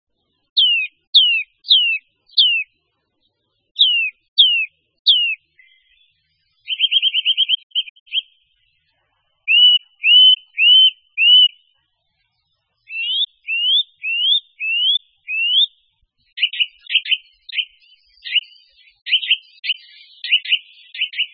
La Sittelle torchepot